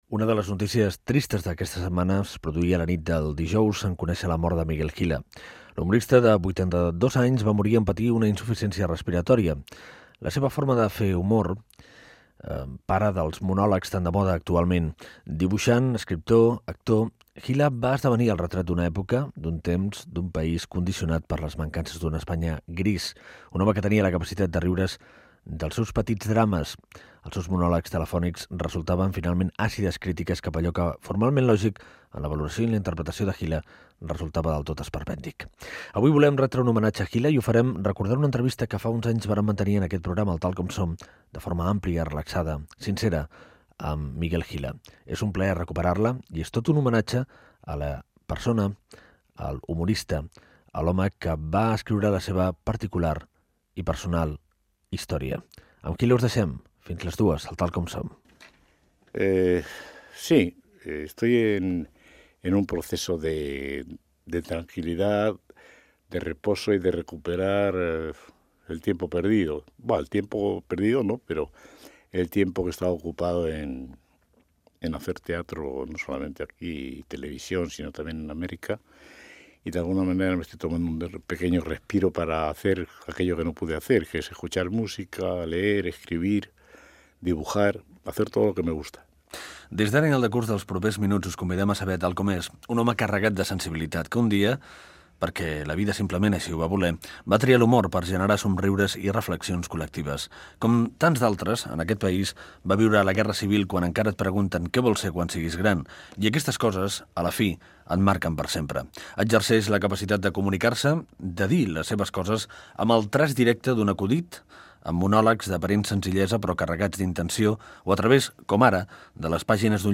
Fragment d'una entrevista amb l'humorista Miguel Gila, enregistrada la temporada 1997/1998 i reemesa després de la seva mort.